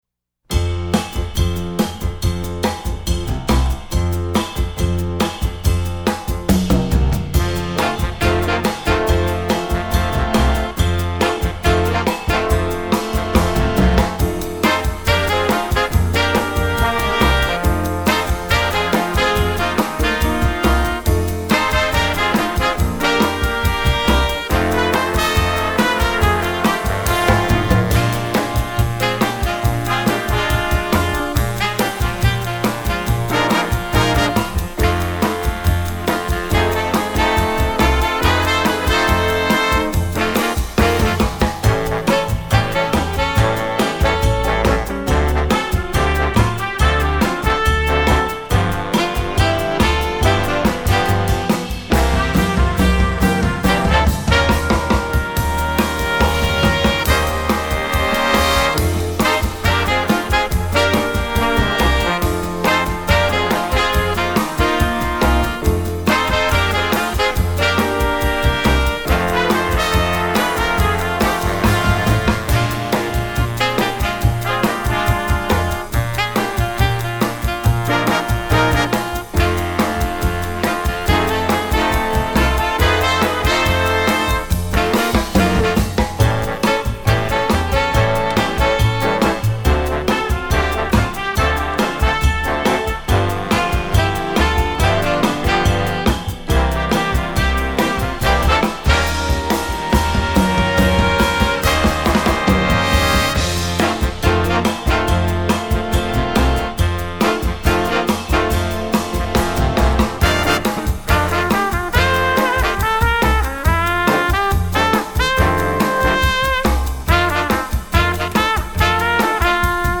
Instrumentation: jazz band